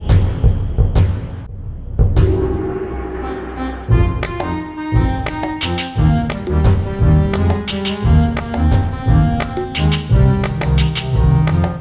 Vals Folk. Transición.
vals
percusión
melodía
Sonidos: Música